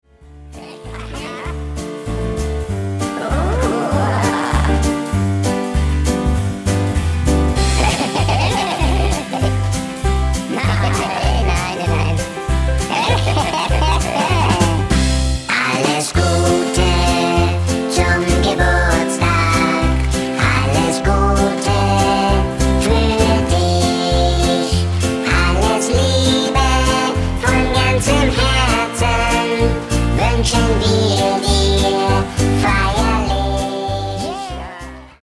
--> MP3 Demo abspielen...
Mit Backing Vocals